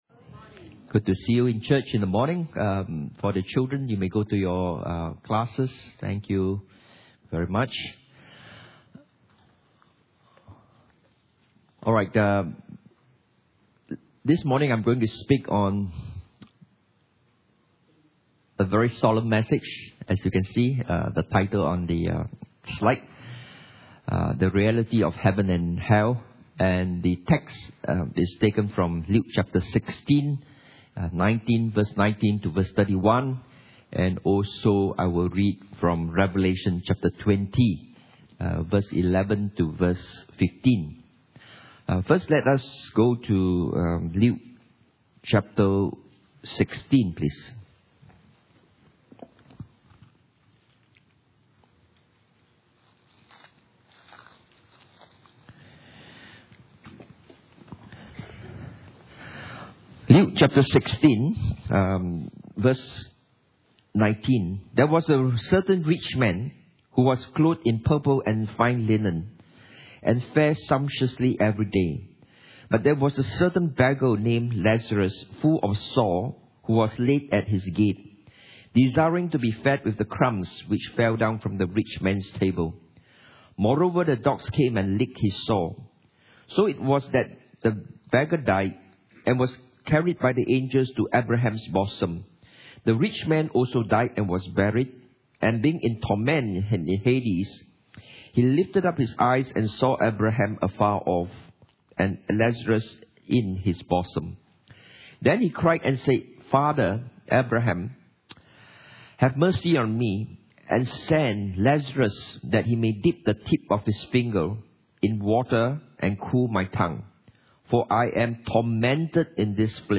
The Reality of Heaven and Hell Service Type: Sunday Morning « Wisdom From Short Stories